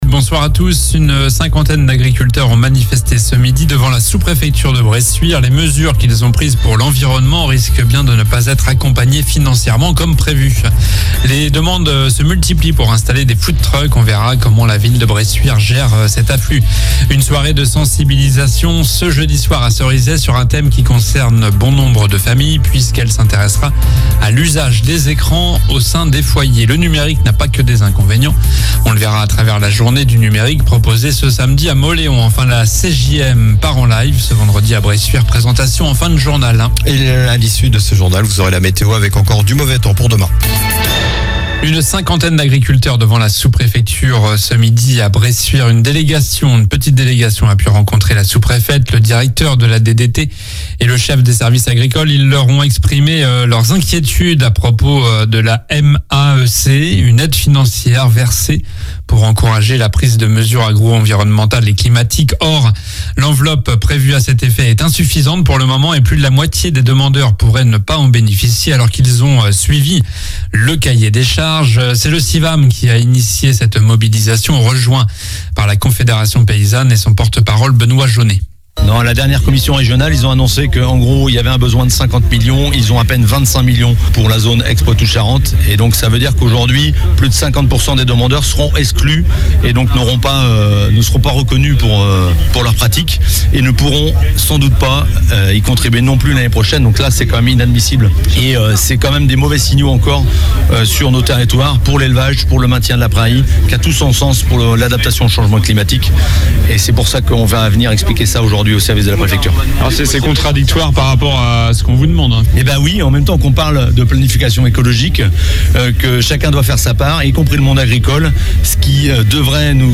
Journal du mercredi 18 octobre (soir)